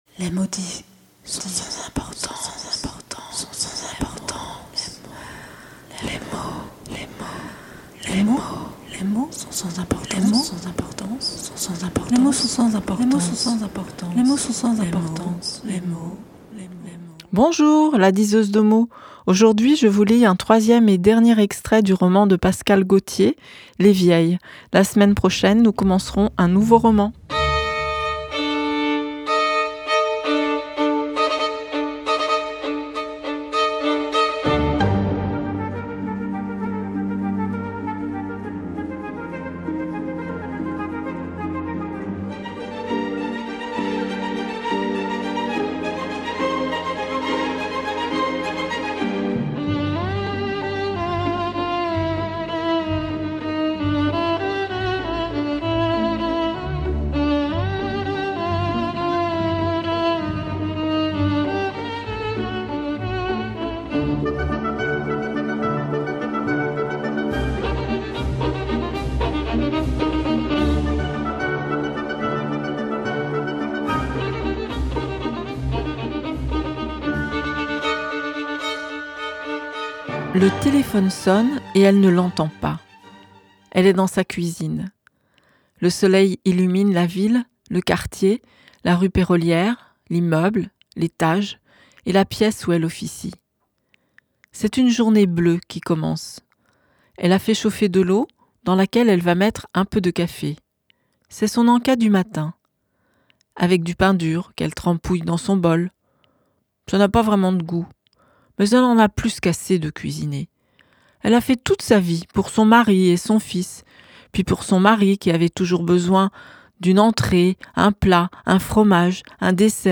3ème extrait de Les Vieilles de Pascale Gautier